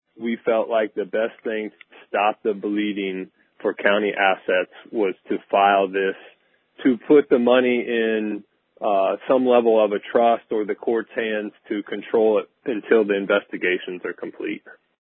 Three Comments From Commissioner Jordan Brewer